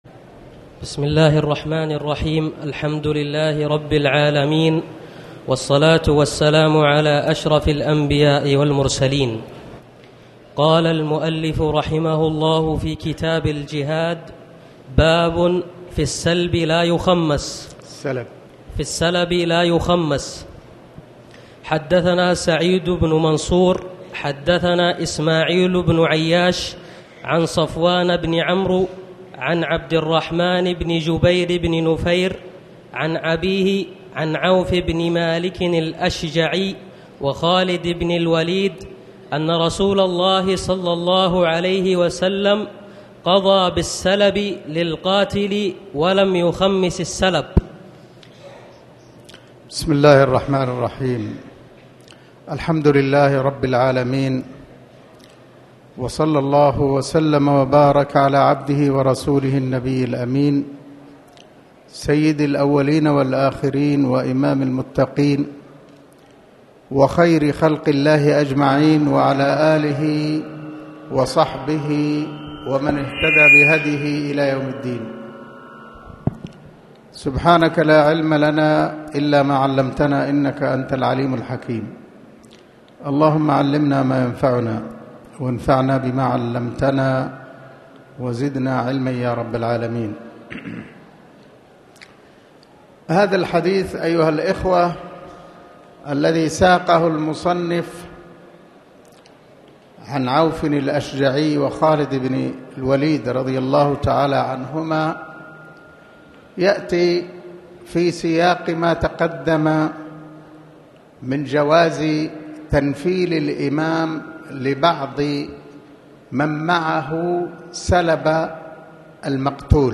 تاريخ النشر ٢١ ربيع الأول ١٤٣٩ هـ المكان: المسجد الحرام الشيخ